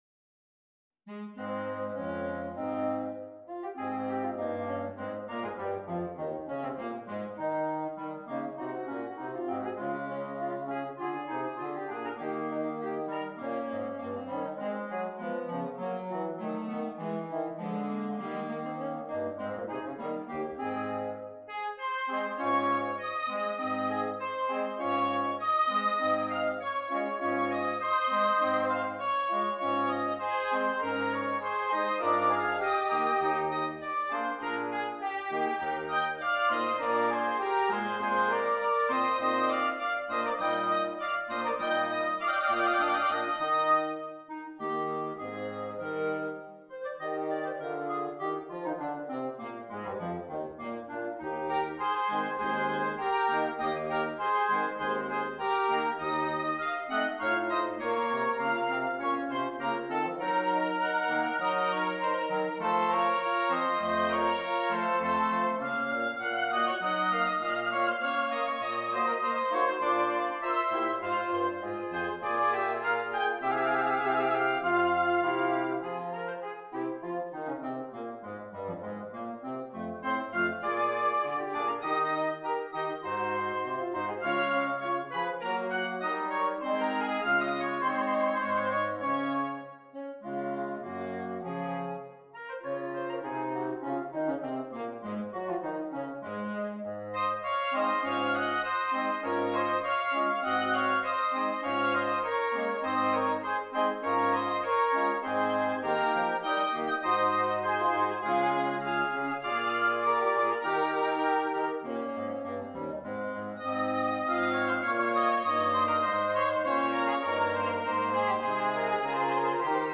Interchangeable Woodwind Ensemble
arranged here for mixed woodwinds